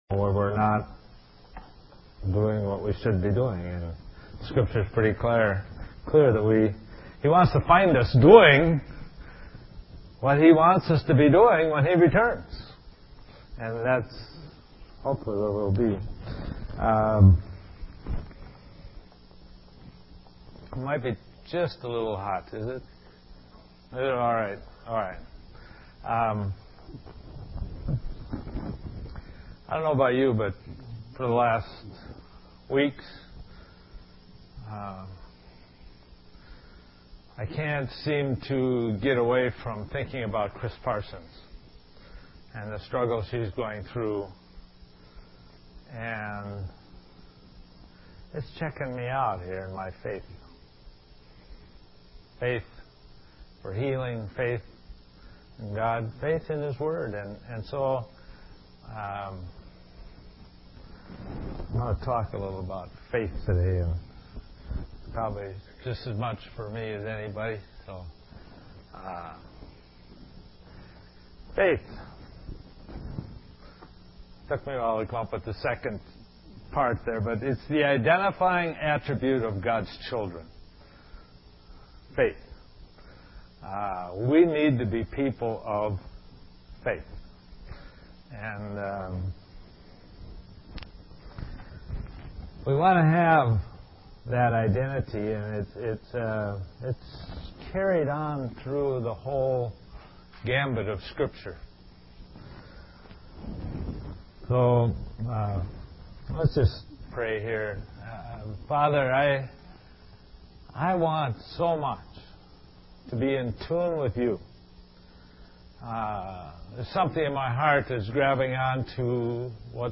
Christmas Service: BELIEVE!